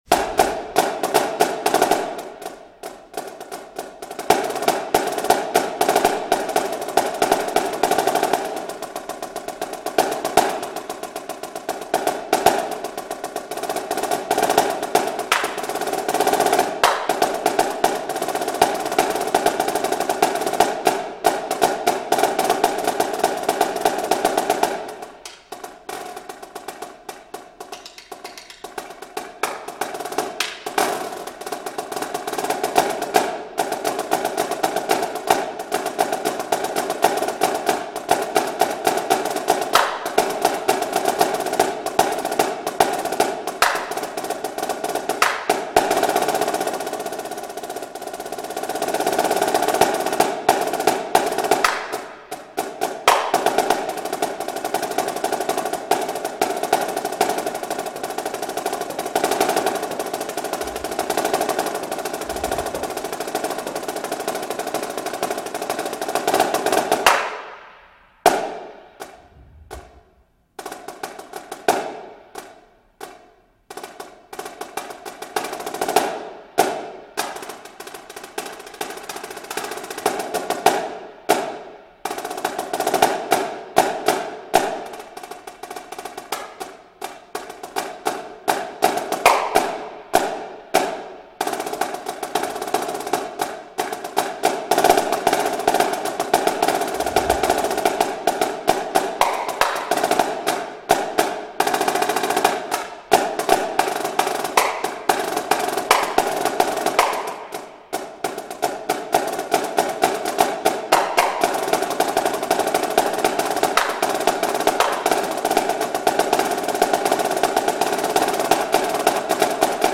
Voicing: Snare Drum